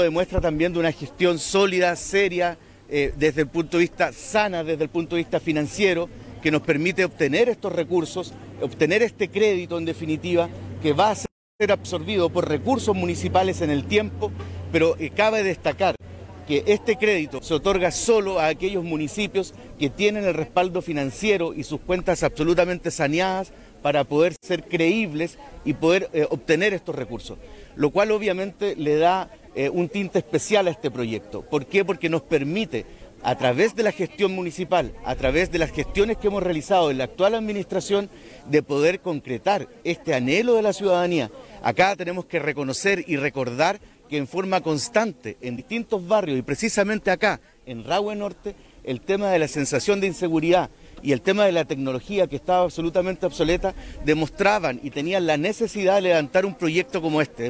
Este martes, se dio inicio al recambio de 15.872 luminarias en el radio urbano de Osorno, en un acto celebrado en la Plaza España de Rahue Alto.
Además, se mencionó que con recursos municipales también se está avanzando en el recambio de luminarias de distintos sectores rurales, para que estas mejoras sean equitativas en toda la comuna de Osorno, según enfatizó el Alcalde (S) Claudio Villanueva.